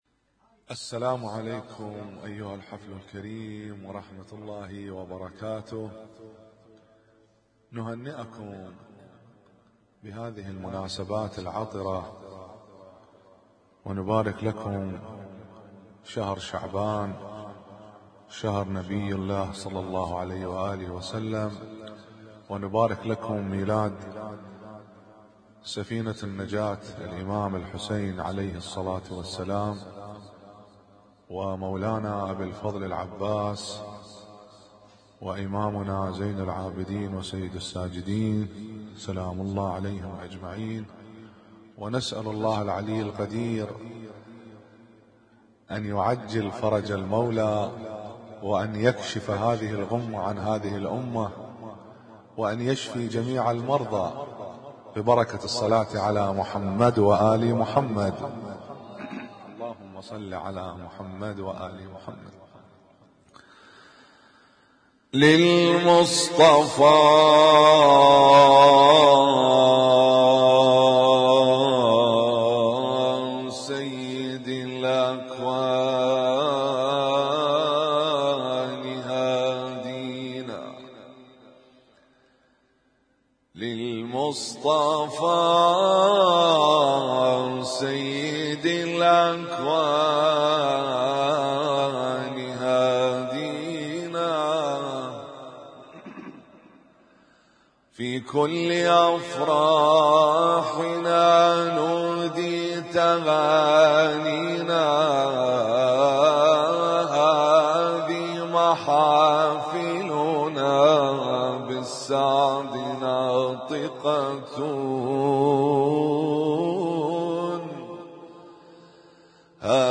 اسم التصنيف: المـكتبة الصــوتيه >> المواليد >> المواليد 1441
القارئ: الرادود